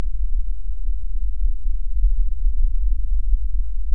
Infraschall
Die Zeitfunktionen wurden mit Tiefpaß 50 Hz, -24dB/Octave gefiltert.
Leider kartiert der Infraschall in keiner der Aufnahmen auf das zugehörige Foto, er kommt von einer unbekannten Quelle außerhalb des Bildfeldes. Achtung: Mit dem Handy ist vom Infraschall absolut nichts zu hören.